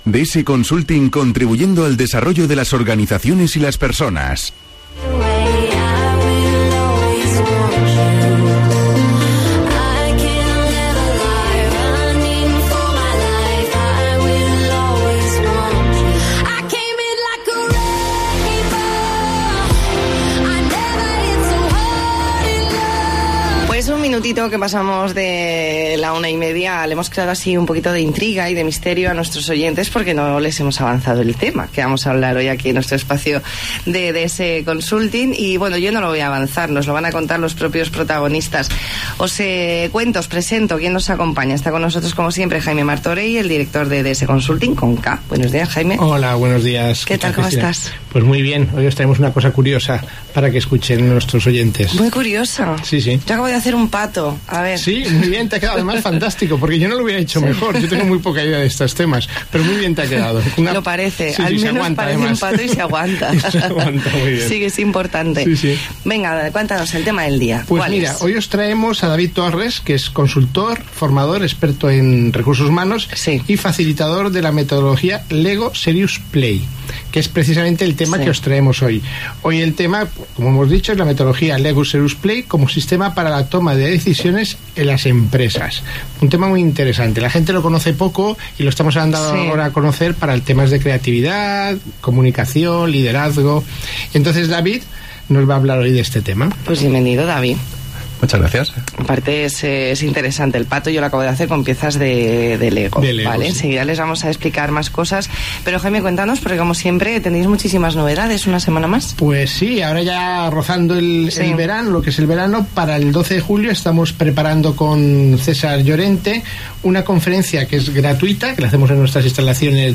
Entrevista en La Mañana en COPE Más Mallorca, lunes 20 de mayo de 2019.